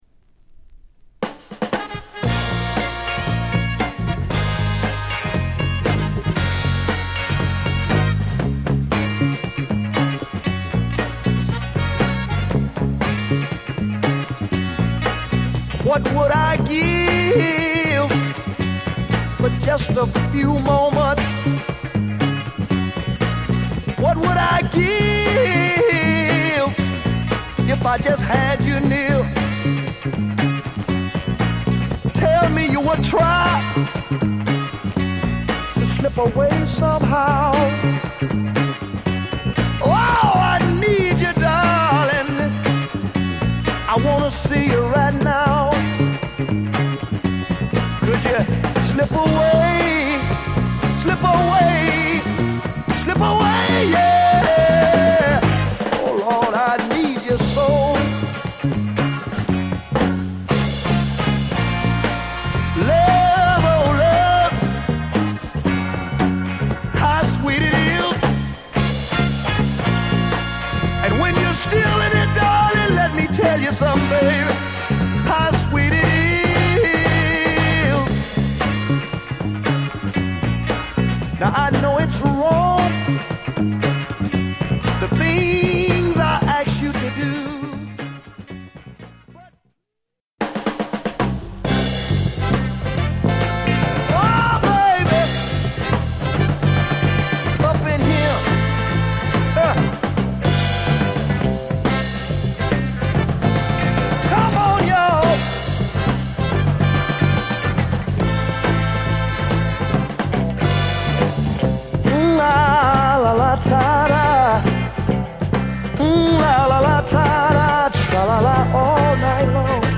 幅広いバックトラックに合わせ瑞々しいヴォーカルを披露した傑作アルバム！